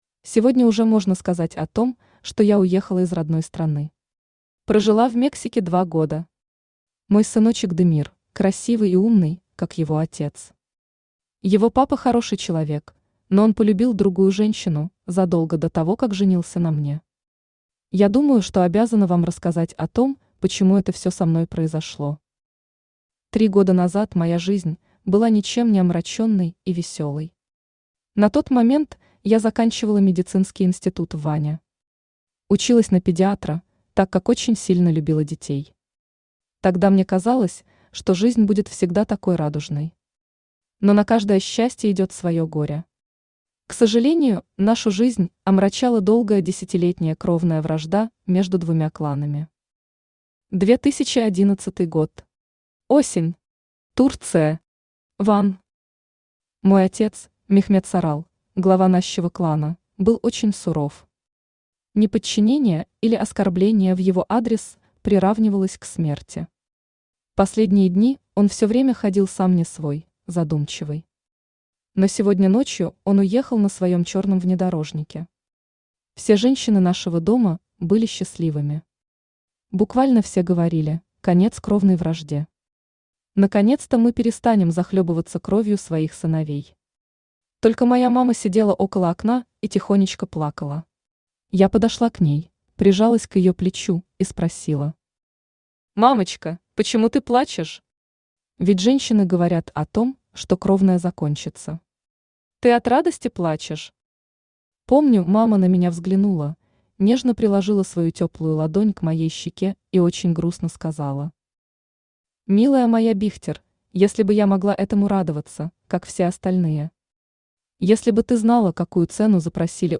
Аудиокнига Свадьба на костях. Часть 1 | Библиотека аудиокниг
Часть 1 Автор Анна Сергеевна Байрашная Читает аудиокнигу Авточтец ЛитРес.